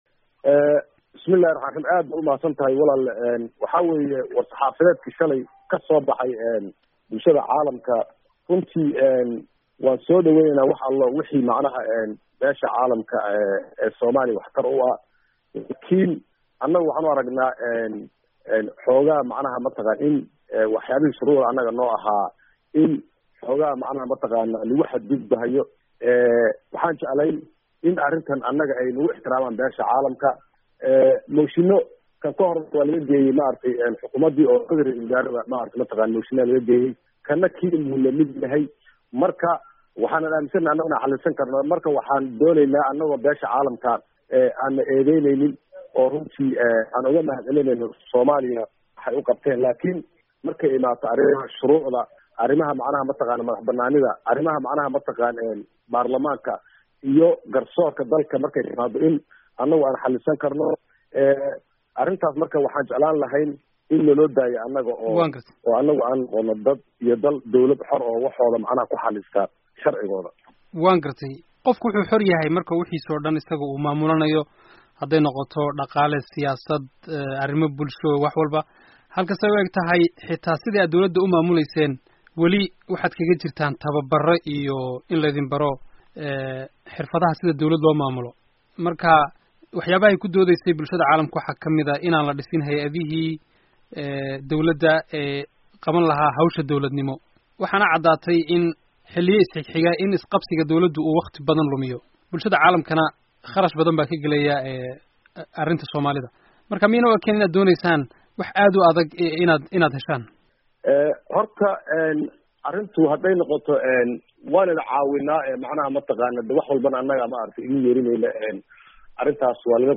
Wareysi: Xildhibaan Shuuriye